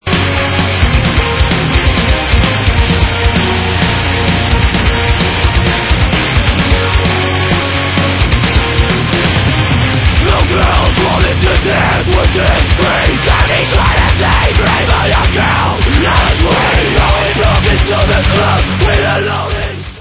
POST-HARDCORE WITH ELECTRONIC APPROACH